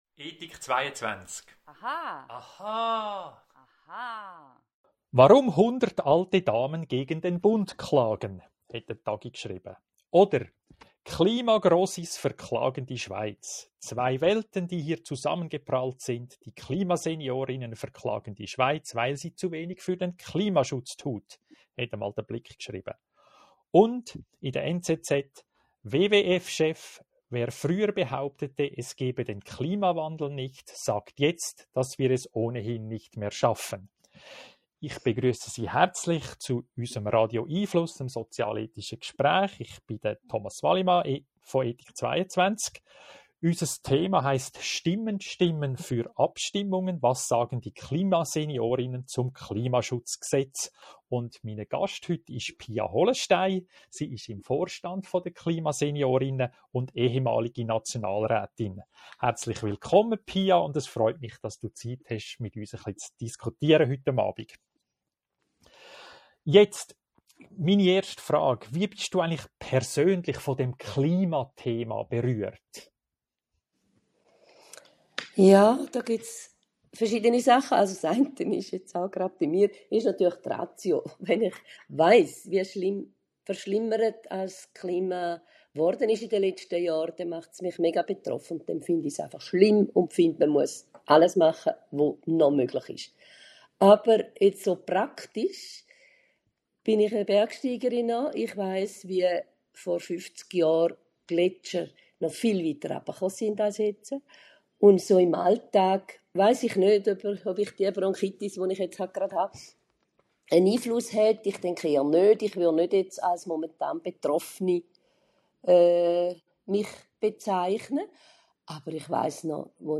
Weitere interessante Überlegungen zum Thema hören Sie im Podcast unseres Gesprächs vom
24. Mai mit Pia Hollenstein, Vorstand KlimaSeniorinnen und Alt-Nationalrätin.